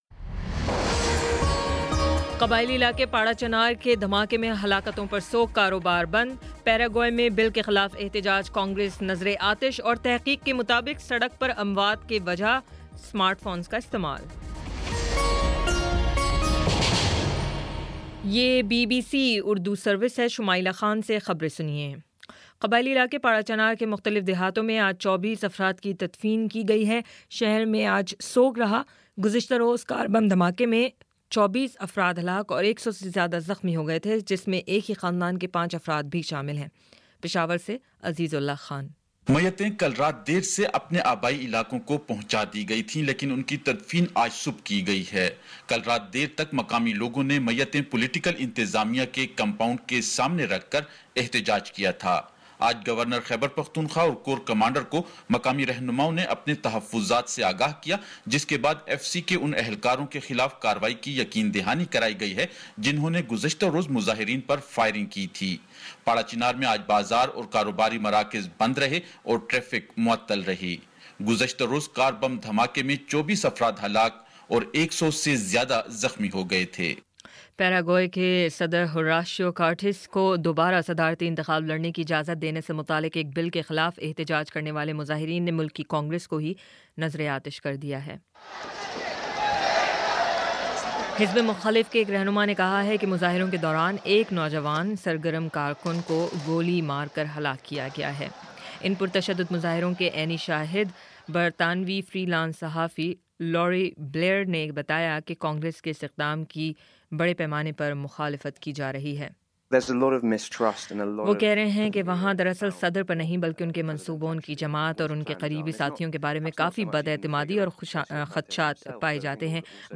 اپریل 01 : شام سات بجے کا نیوز بُلیٹن